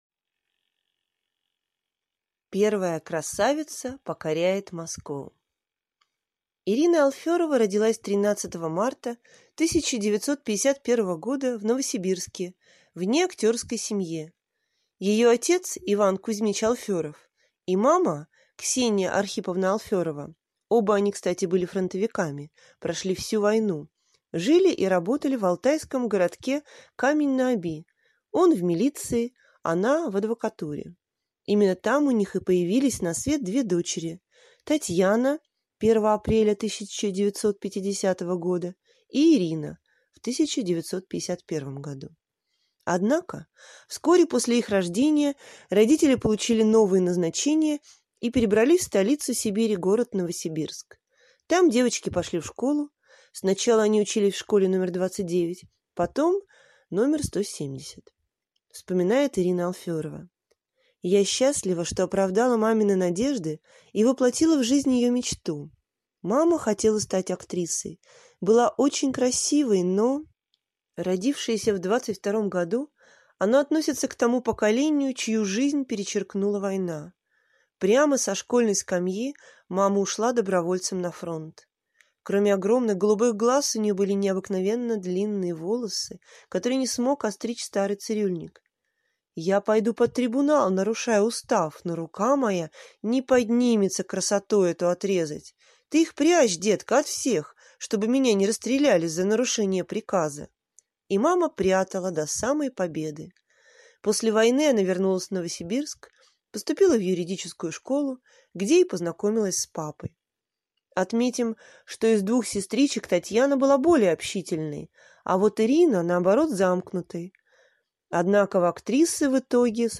Аудиокнига Ирина Алферова. Любимая женщина красавца Абдулова | Библиотека аудиокниг